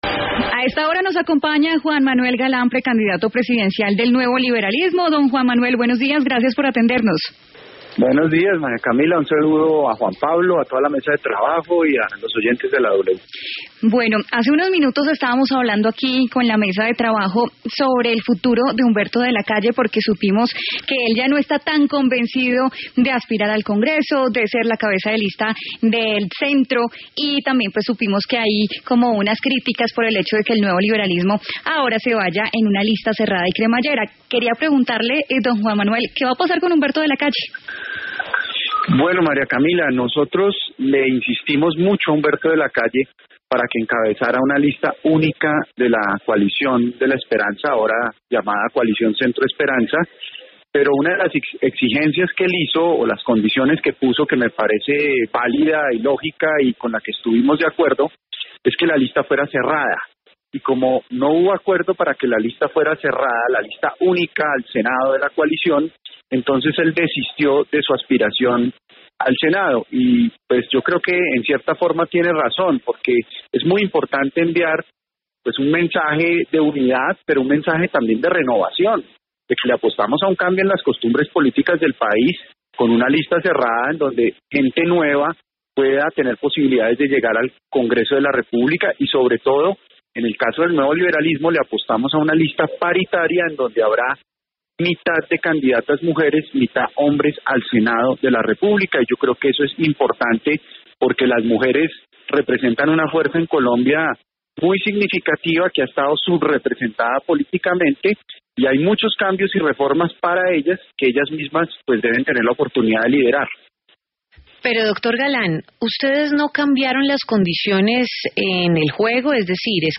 En diálogo con La W, el precandidato presidencial, Juan Manuel Galán, explicó que Humberto de la Calle había puesto una condición y era encabezar una lista de centro cerrada.